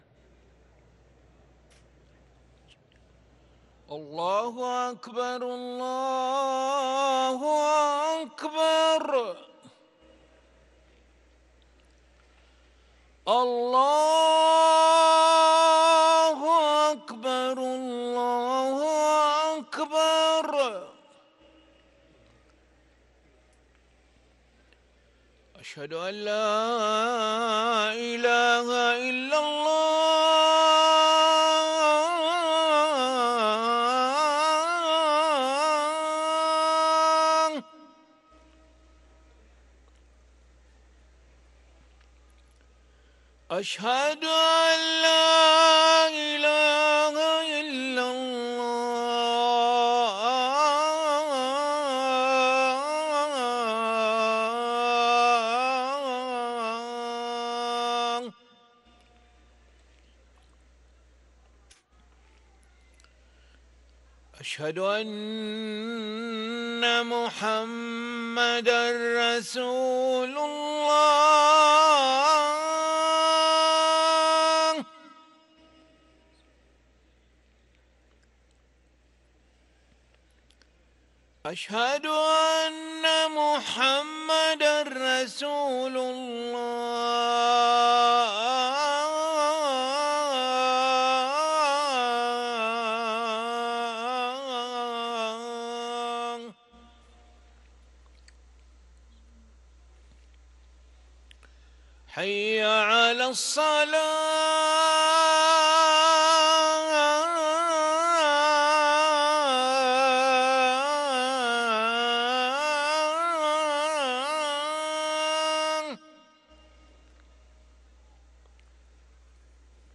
أذان العشاء للمؤذن علي ملا الأحد 8 جمادى الآخرة 1444هـ > ١٤٤٤ 🕋 > ركن الأذان 🕋 > المزيد - تلاوات الحرمين